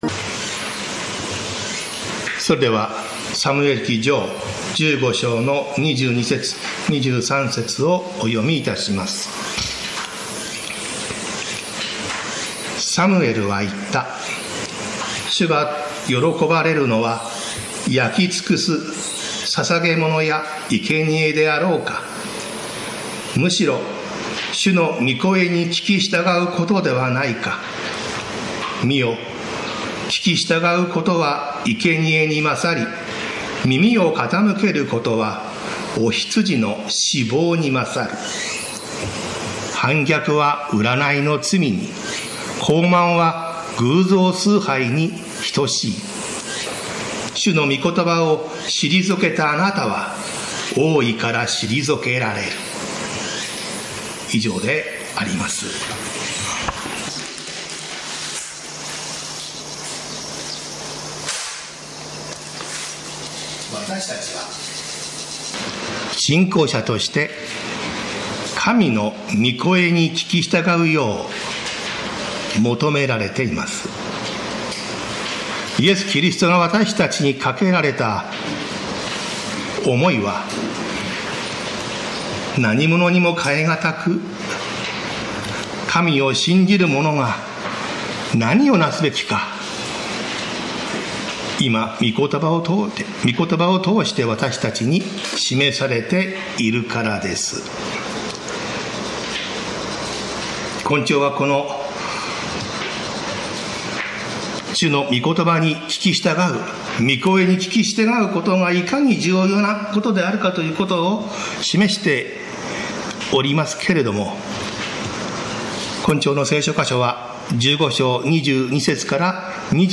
宇都宮教会 礼拝説教